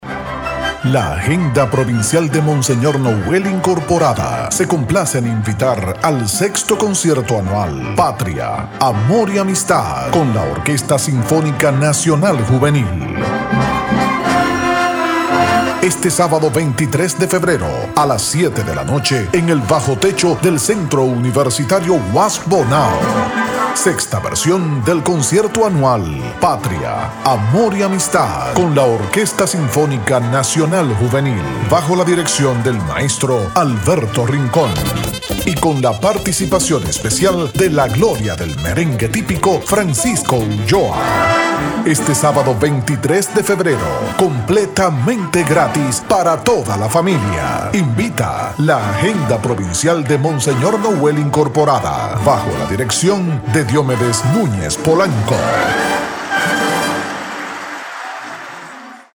Potente voz masculina, ideal para comerciales institucionales y de eventos
Sprechprobe: Werbung (Muttersprache):
Powerful male voice, ideal for institutional and event commercials